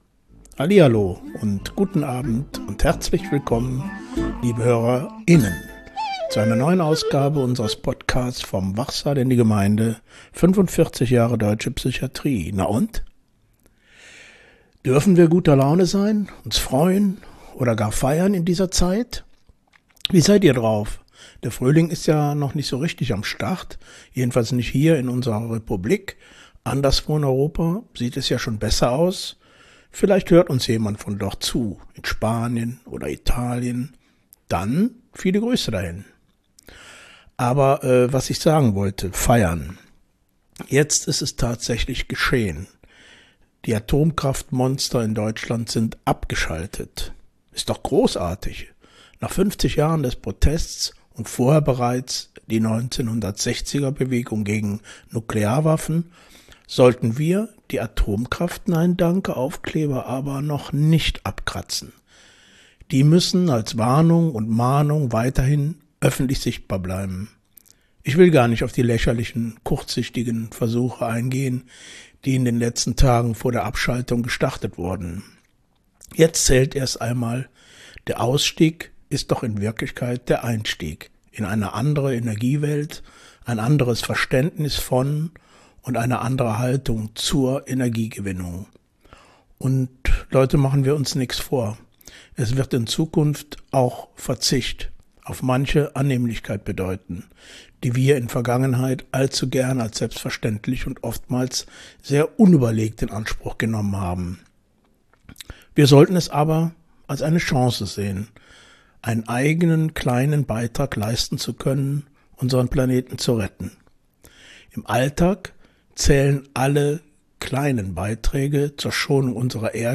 Bereichert mit Interviews von Insidern aus den verschiedensten Perspektiven. In jeder Folge wird zudem ein Song aus der Rock- und Popgeschichte gespielt und der jeweilige Künstler vorgestellt.